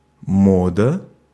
Ääntäminen
France (Paris)
IPA: /mɔd/